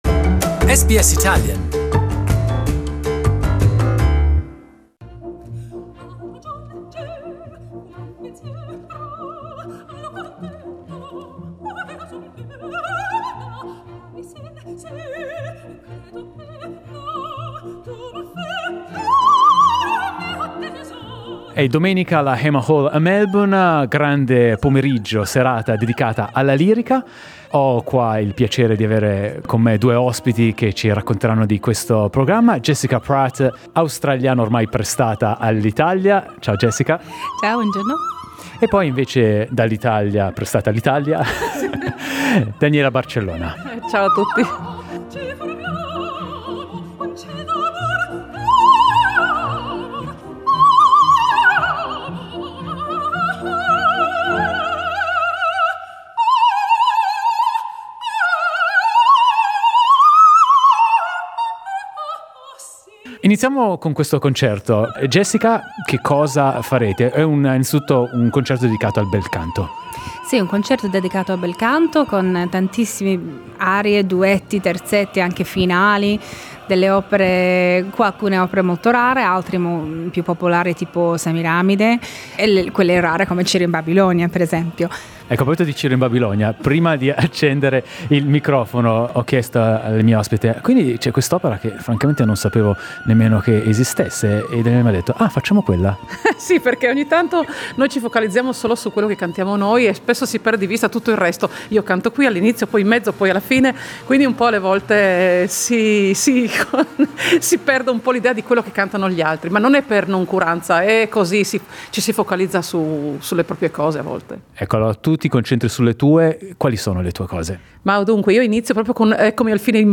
Jessica Pratt and Daniela Barcellona talk music, arts and glamorous Opera jet setting with SBS Italian.